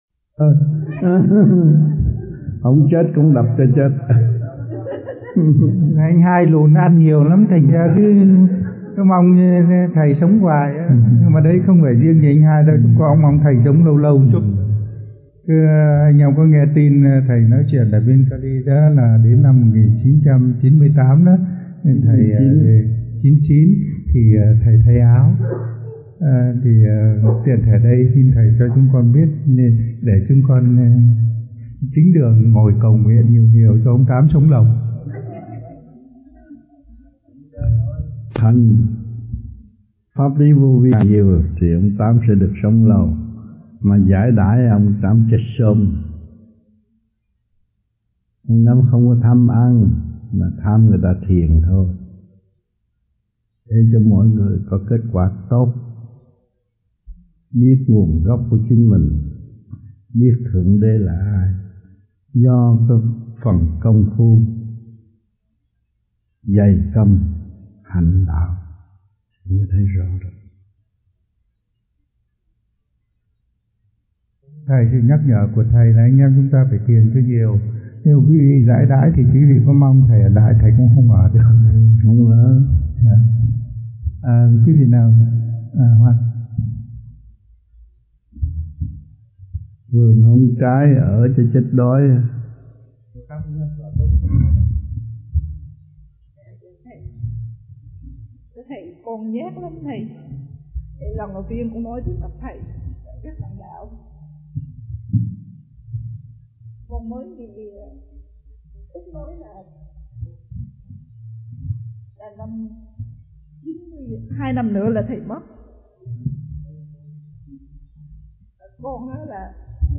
United States Trong dịp : Sinh hoạt thiền đường >> wide display >> Downloads